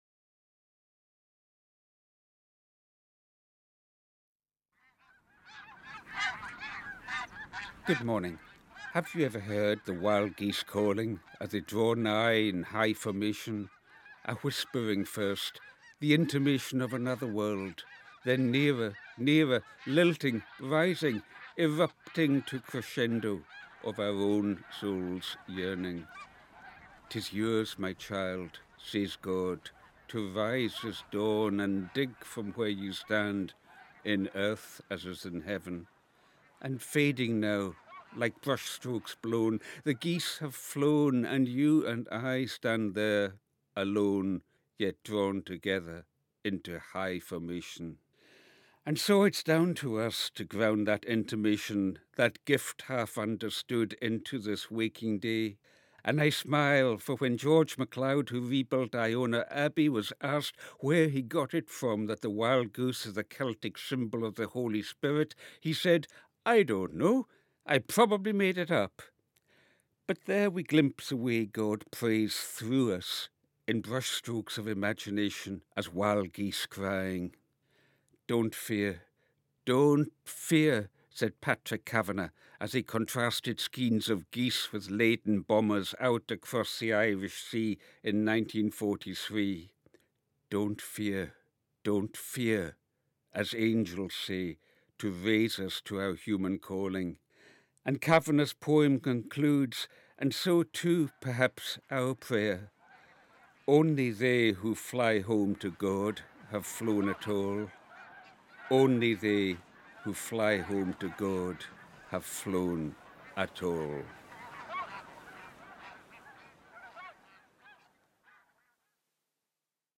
BBC Radio 4, "Prayer for the Day", broadcast 0543 Fri 27 Jun 2025,